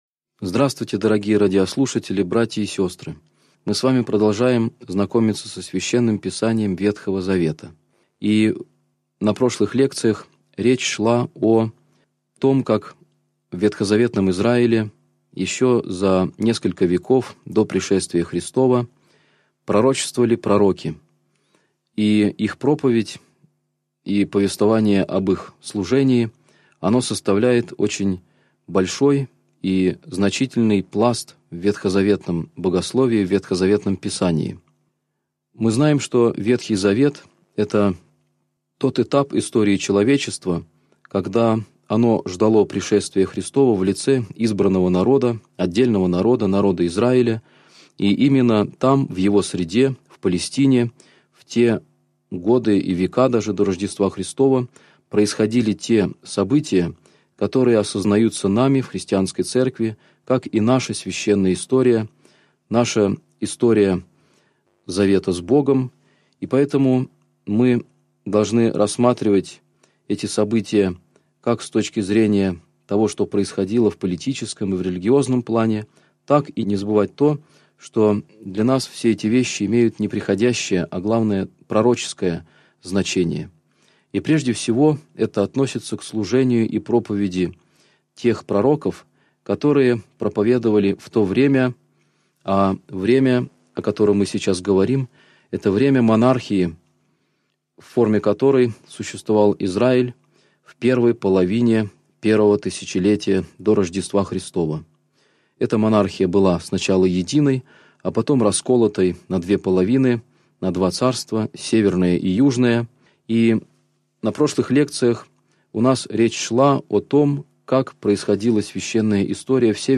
Аудиокнига Лекция 12. Пророк Михей. Пророк Исаия | Библиотека аудиокниг